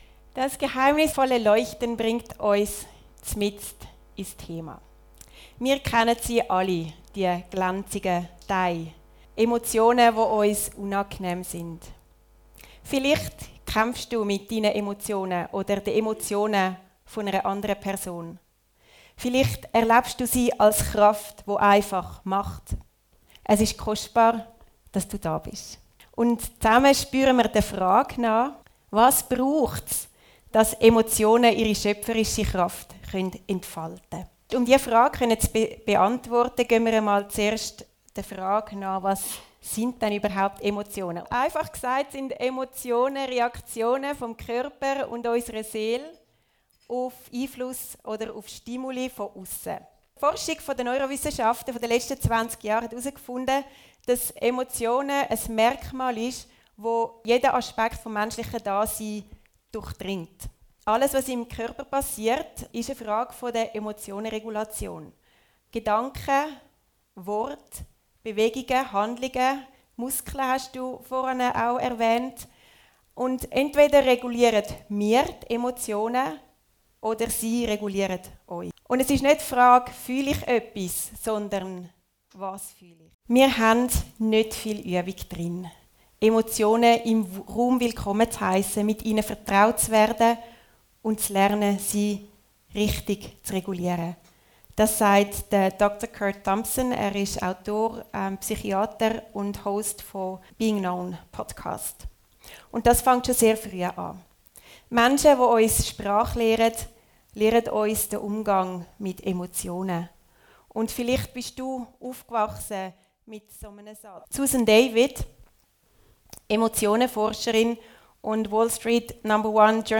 Audio trailer “Die schöpferische Kraft von Emotionen” (German)